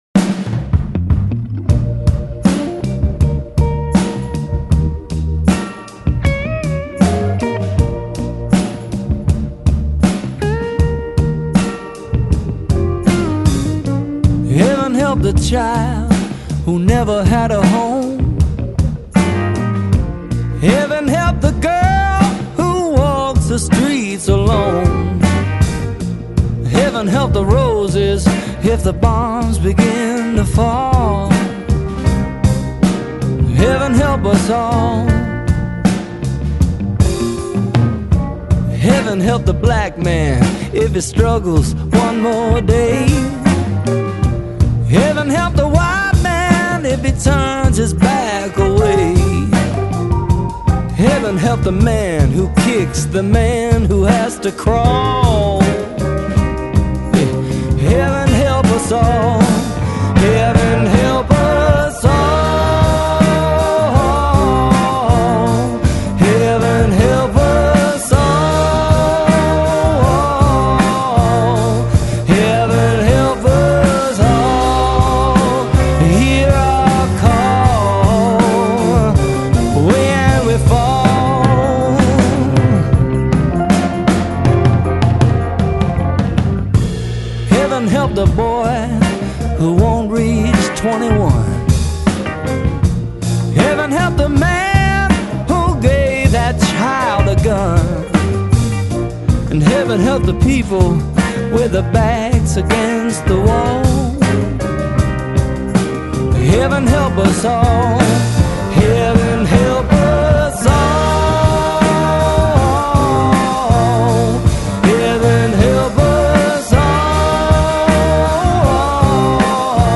It was somber but also very peaceful.